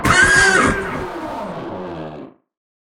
PixelPerfectionCE/assets/minecraft/sounds/mob/horse/zombie/hit3.ogg at f70e430651e6047ee744ca67b8d410f1357b5dba